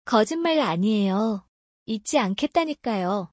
コジンマ アニエヨ. イッチ アンケッタニカヨ